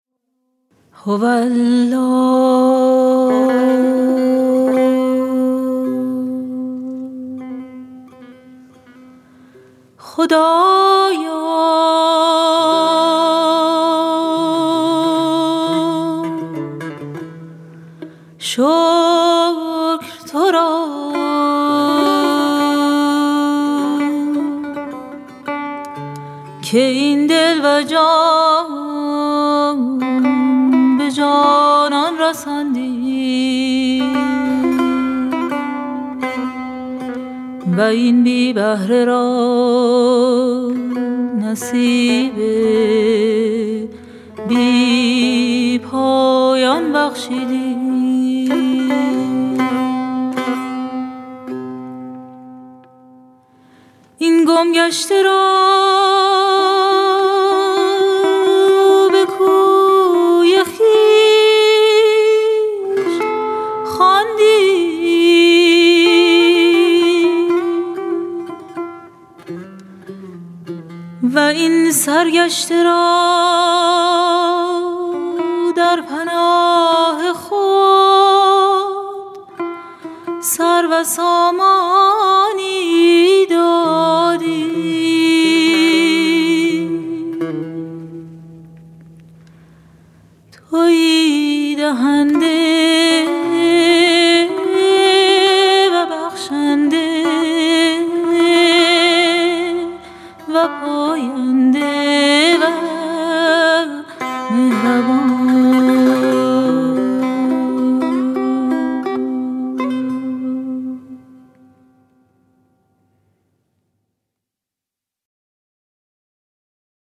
مجموعه مناجات های فارسی همراه با موسیقی